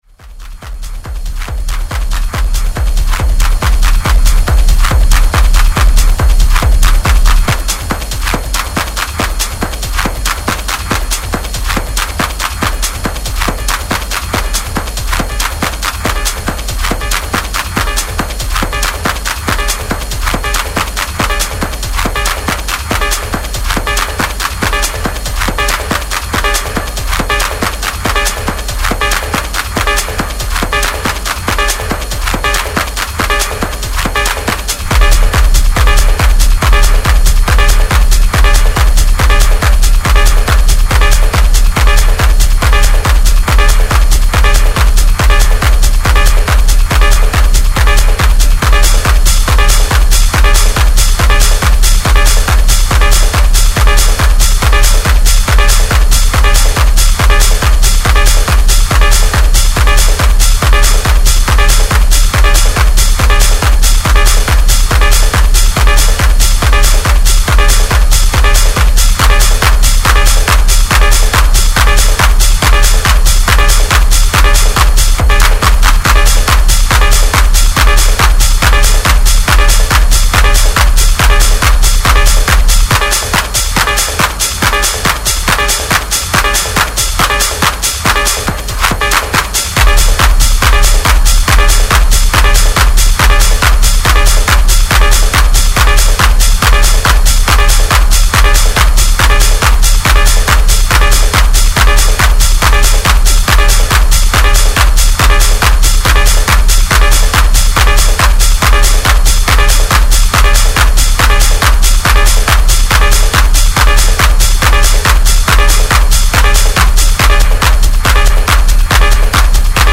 Hard hitting techno stompers